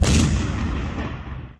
WULA_MW_Artillery_Shootingsound.wav